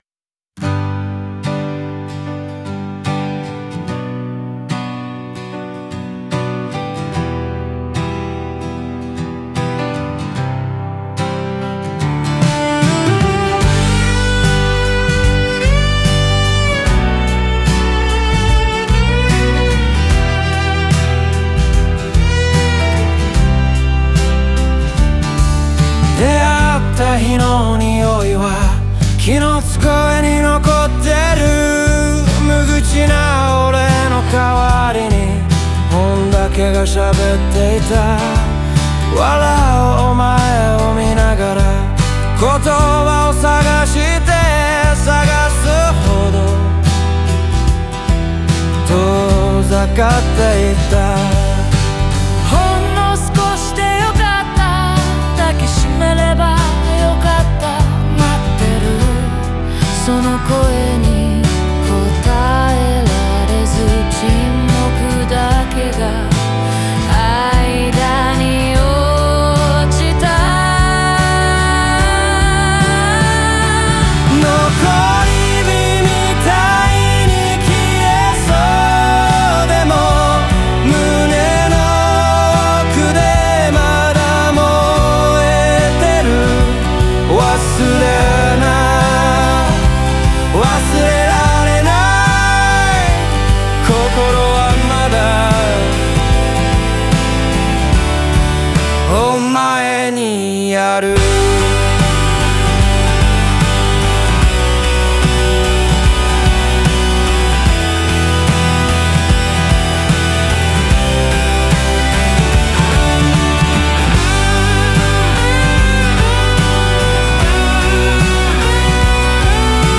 オリジナル曲♪
抑えた語りと感情の解放が交互に現れ、聴く者に静かな切なさと共感を呼び起こす。